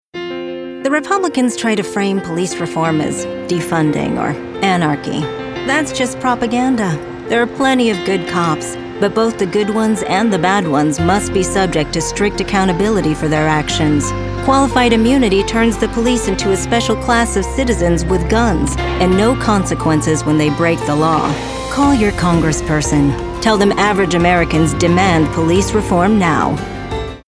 Political Voice Demos
Professional Female Political Voiceover
• Home Studio